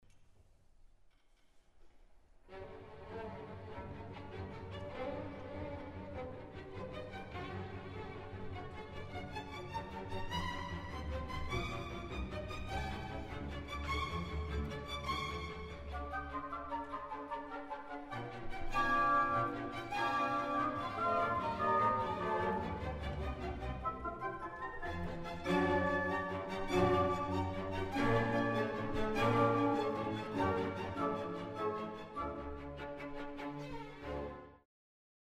Example 4 – Opening of Scherzo: